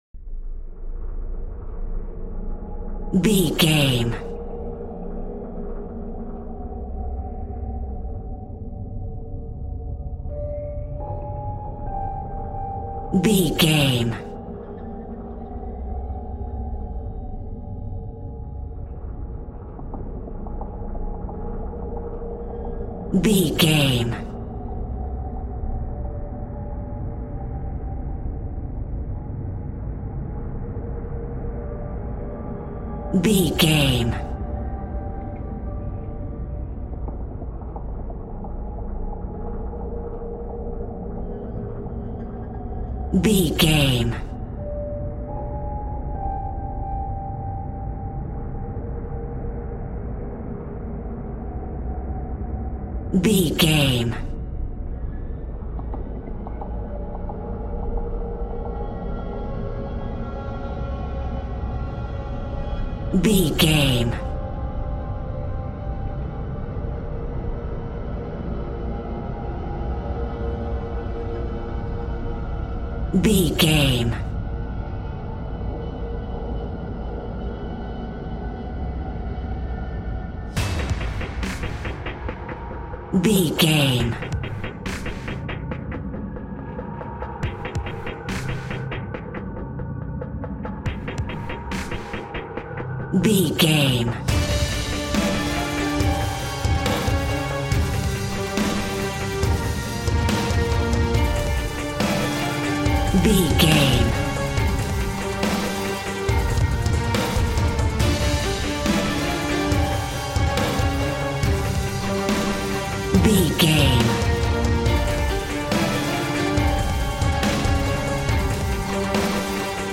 Aeolian/Minor
D
tension
dramatic
synthesizer
percussion
pads